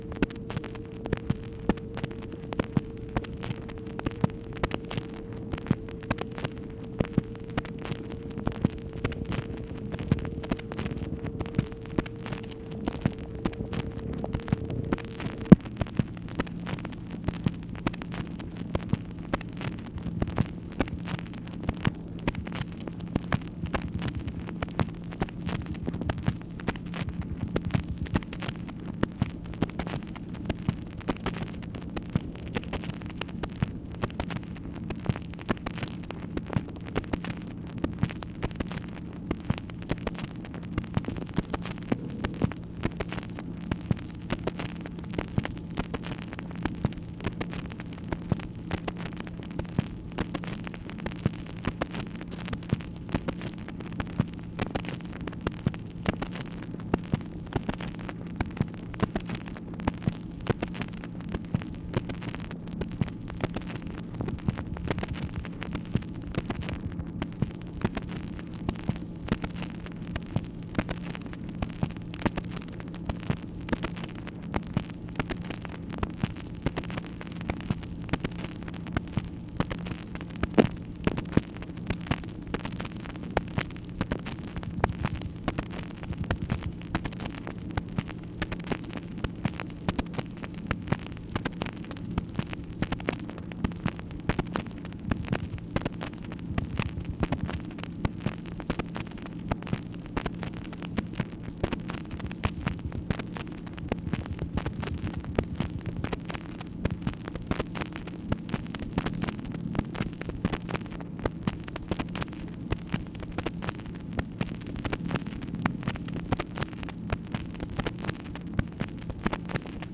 MACHINE NOISE, March 9, 1964
Secret White House Tapes | Lyndon B. Johnson Presidency